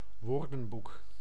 Ääntäminen
IPA: [ʋoːɾ.dǝn.buk]